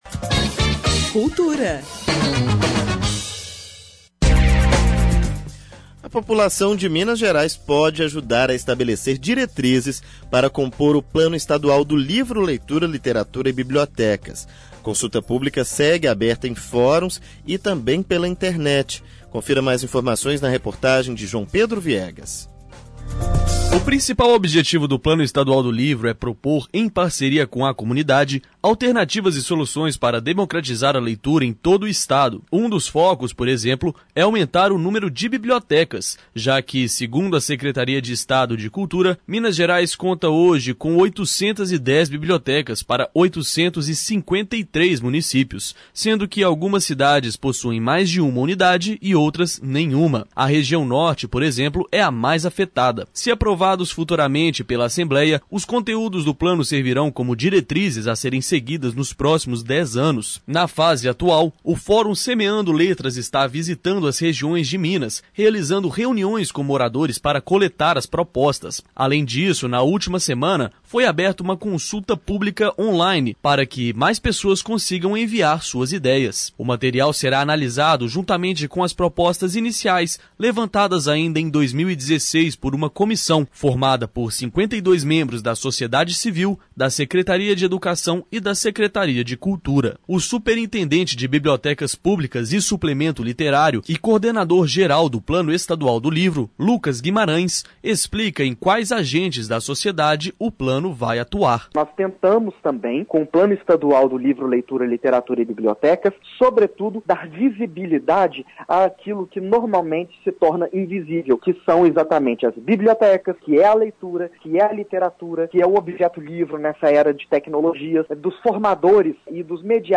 Jornalismo